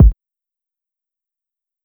Kick Stargazing.wav